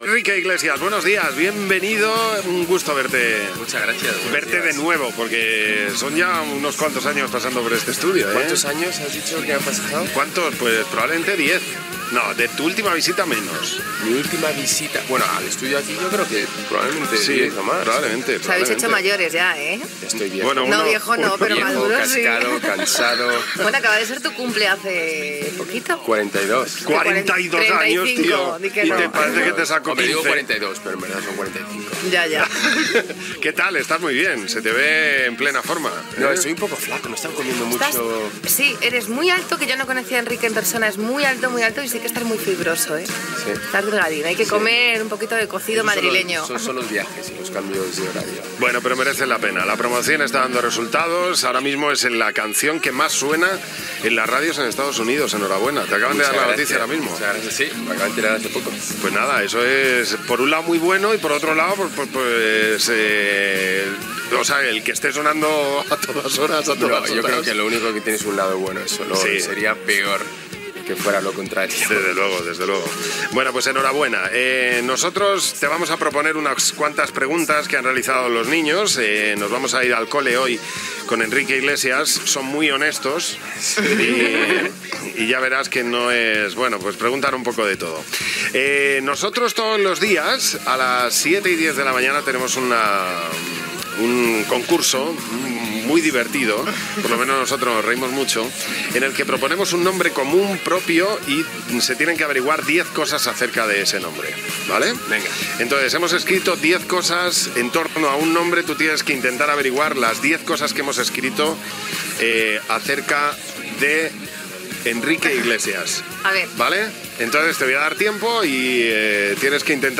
Entrevista al cantant Enrique Iglesias que també contesta les preguntes fetes per nens i nenes